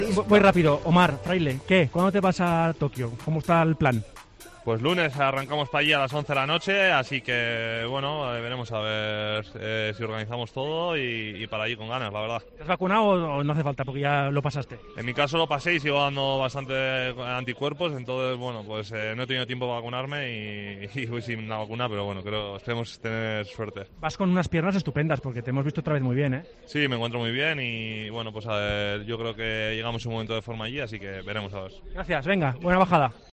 AUDIO: El corredor de Astana comparte sus sensaciones y se prepara para ir a los Juegos.